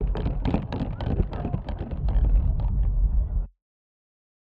spinning-wheel.a1e5be74.wav